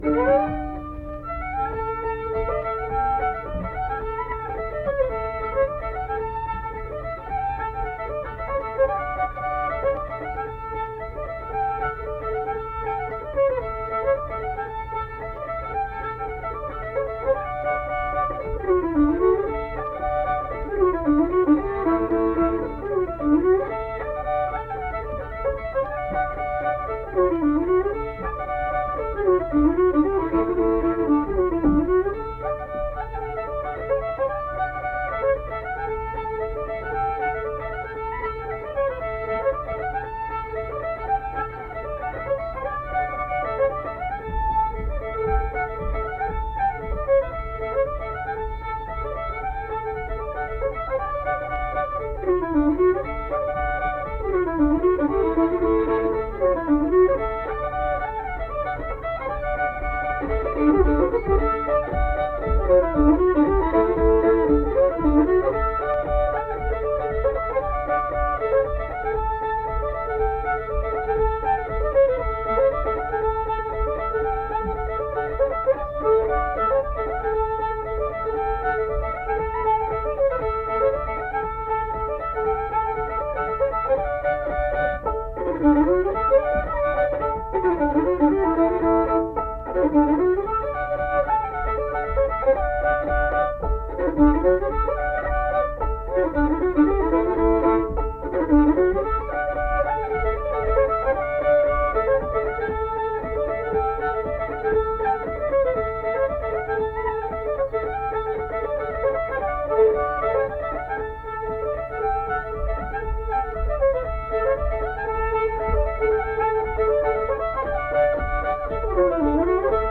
Accompanied guitar and unaccompanied fiddle music performance
Instrumental Music
Fiddle
Mill Point (W. Va.), Pocahontas County (W. Va.)